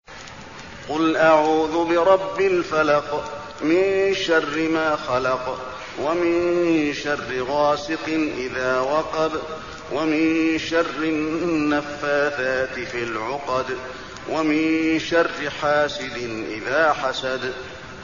المكان: المسجد النبوي الفلق